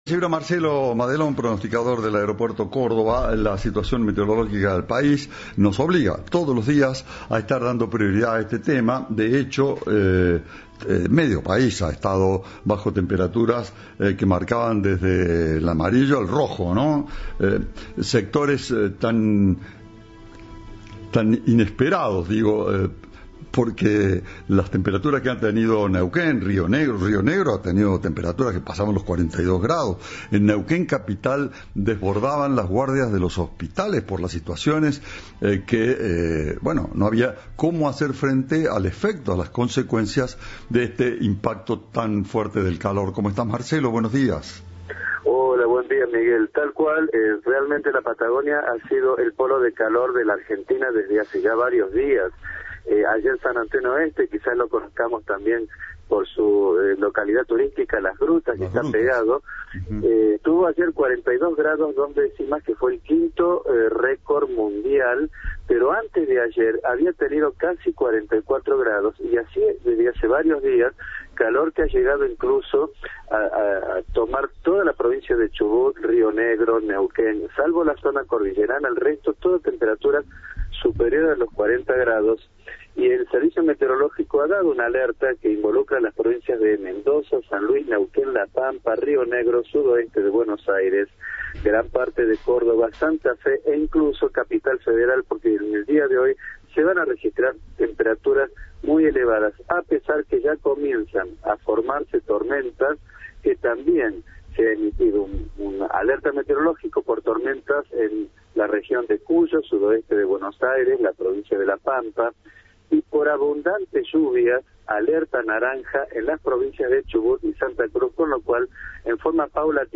Entrevista de Radioinforme 3.